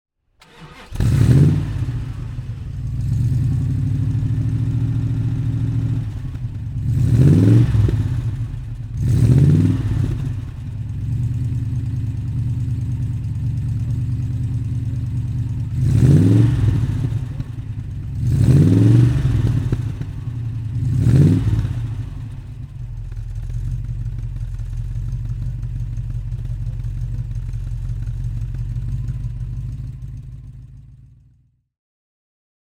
Jensen Interceptor II (1970) - Starten und Leerlauf
Jensen_Interceptor_II.mp3